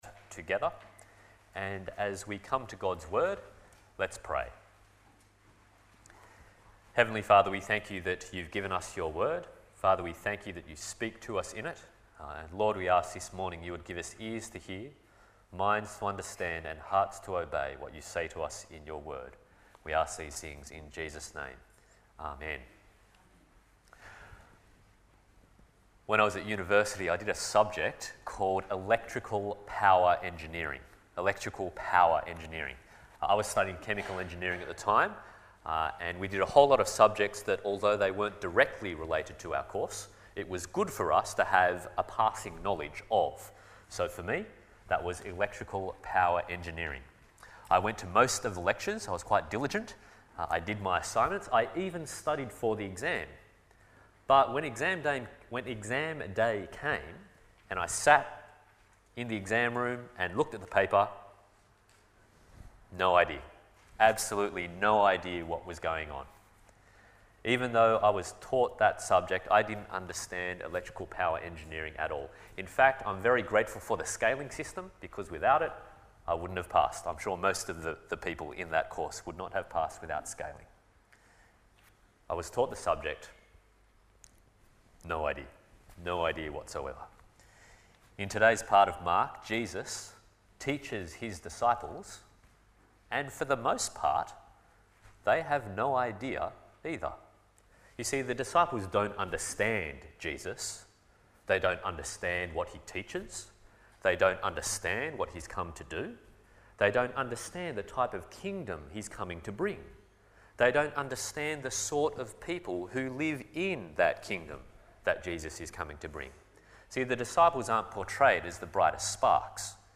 Isaiah 66:15-Mark 66:24 Service Type: Sunday Morning « Listen